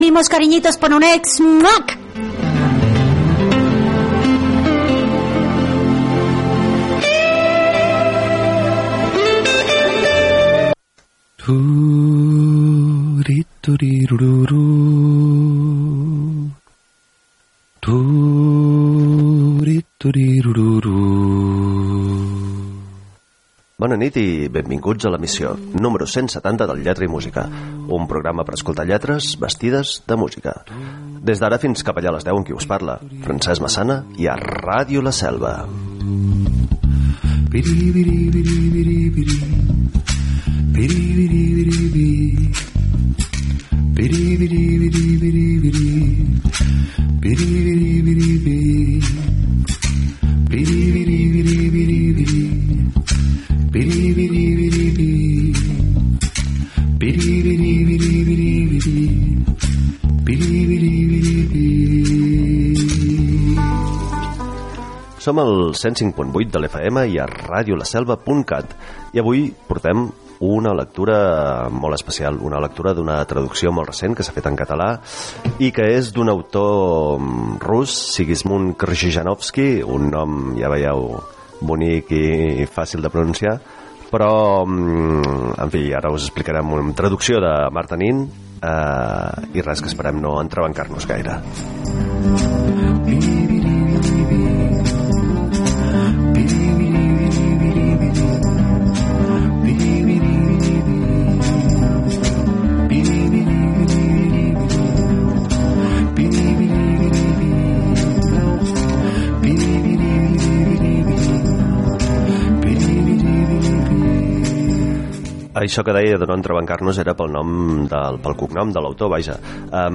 Un programa per escoltar lletres vestides de música. I per llegir textos nus. I per deixar-nos tapar amb músiques sense lletra.